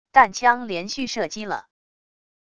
弹枪连续射击了wav音频